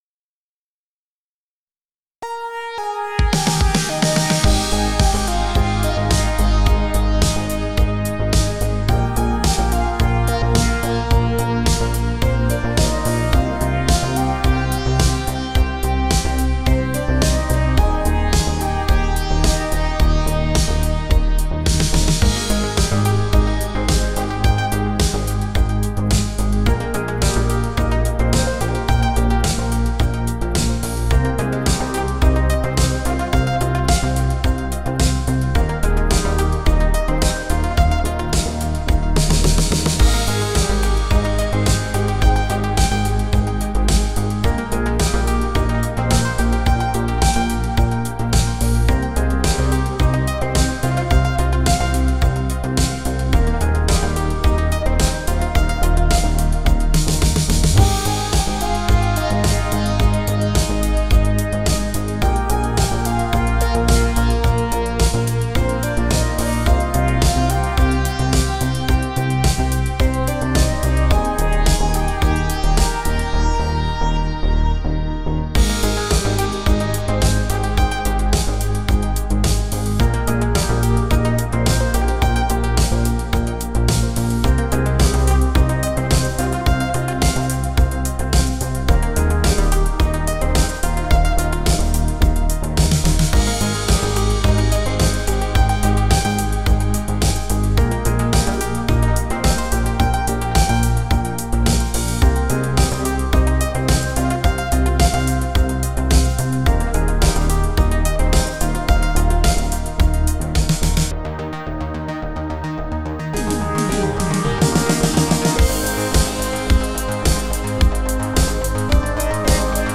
a synthwave pop tune that is begging for some fun lyrics!
this started out as a practice to solo the main synth lead that led to a couple of melodies that I kept and built a bass line around it.. using canned drums from SUNO but it moves the song along enough
BPM: 108 Synths: Arturia Pigments Drums: SUNO
Is it just me, or has anyone else immediate J-Pop vibes after the first notes? :-)